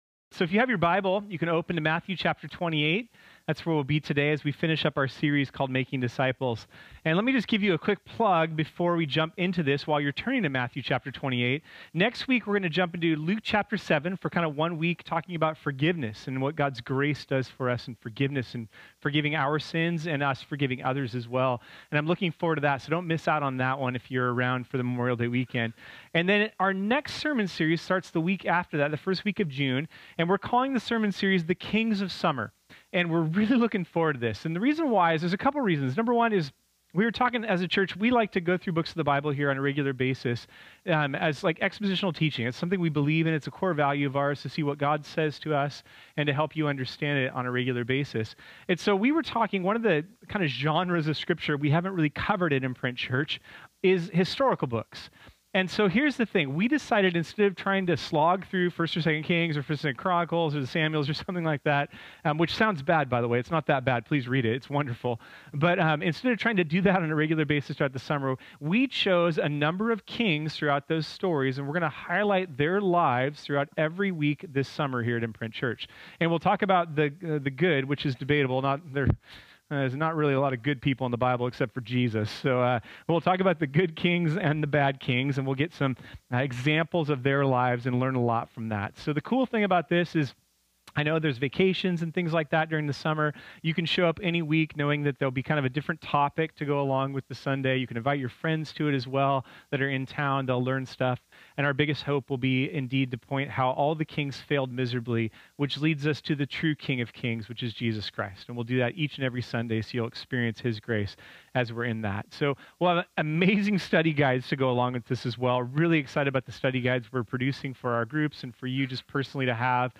This sermon was originally preached on Sunday, May 20, 2018.